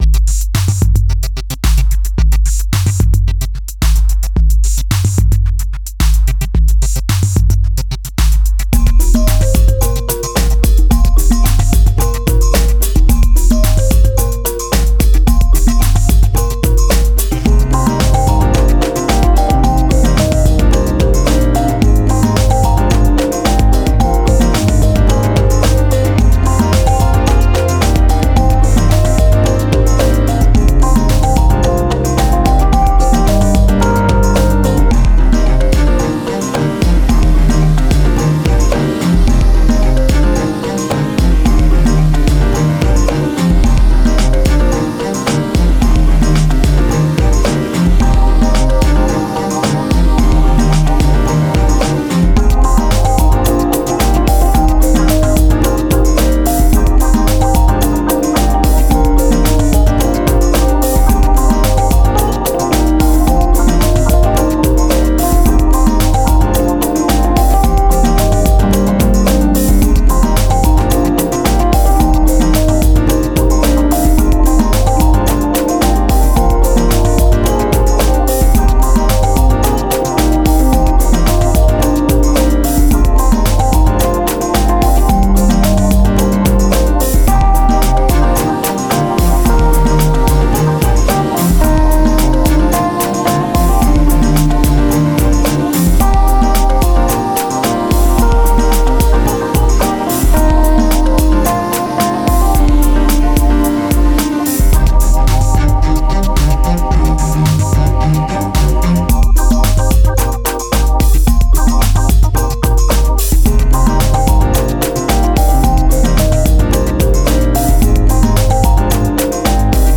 Categories: 2022funkjazz